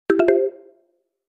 Звук подключения зарядки на Android 4.4